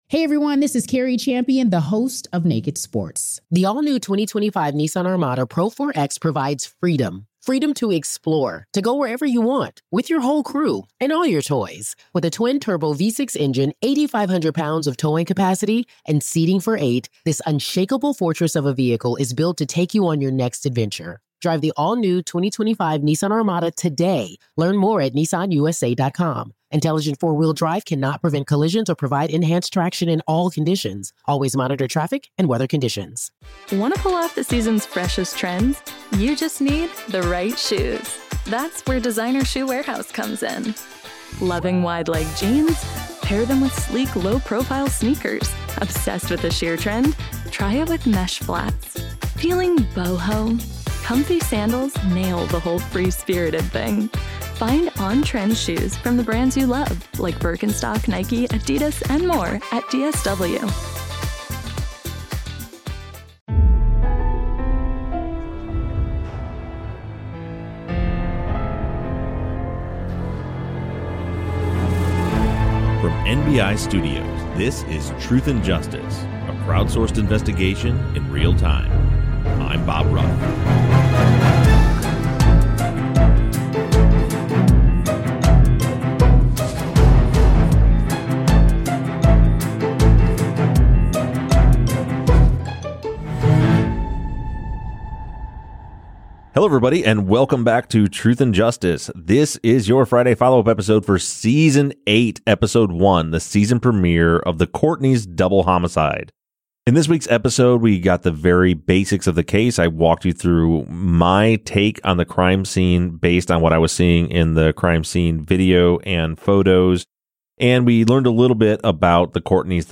The guys discuss their thoughts as well as listener questions about the crime scene information covered in the first episode of Season 8.